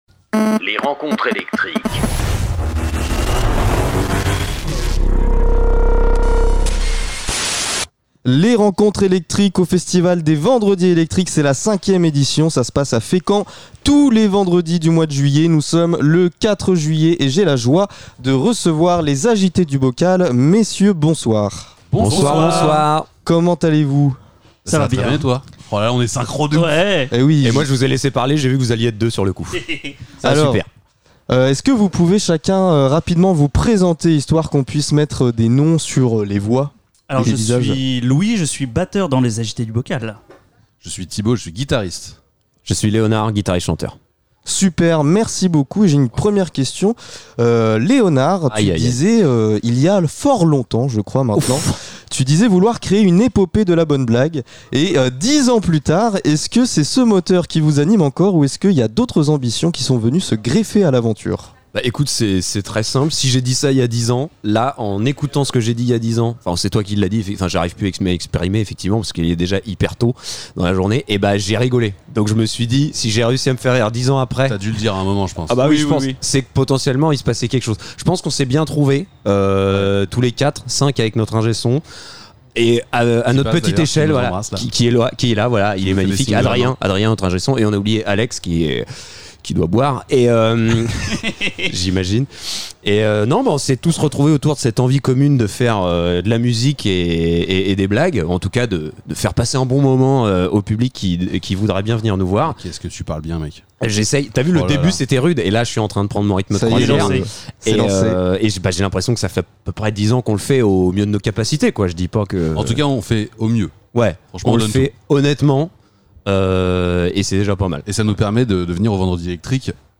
Les rencontres électriques sont les interviews des artistes régionaux qui se produisent lors du festival "Les vendredi électriques" organisés par l'association Art en Sort.